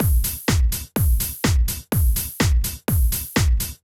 Drumloop 125bpm 01-A.wav